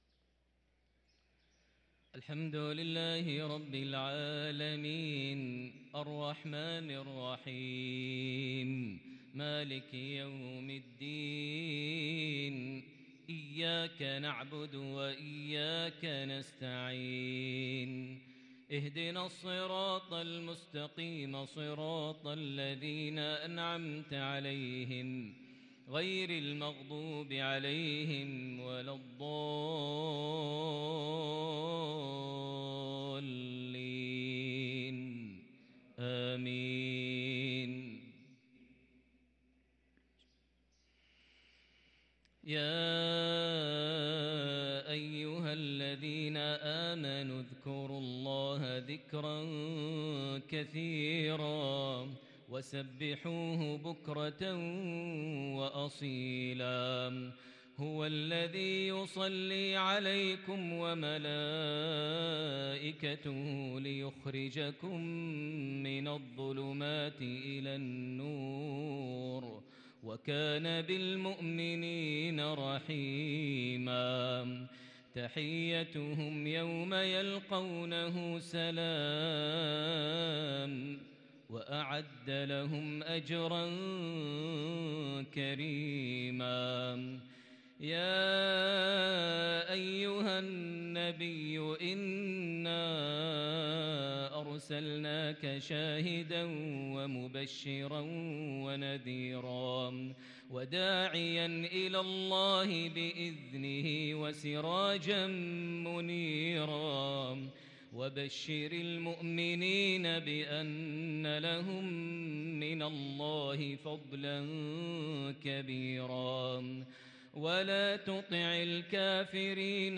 صلاة المغرب للقارئ ماهر المعيقلي 30 ربيع الآخر 1444 هـ